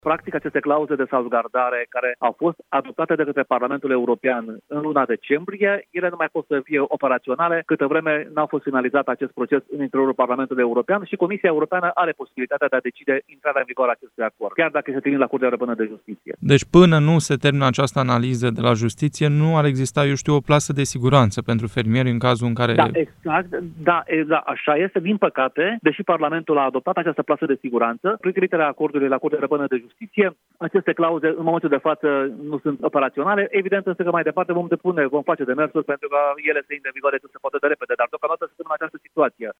Trebuie ca acordul, mai întâi, să fie verificat de Curtea Europeană, pentru ca aceste clauze „plase de siguranță” pentru fermieri să intre în vigoare, ne-a mai declarat europarlamentarul Daniel Buda.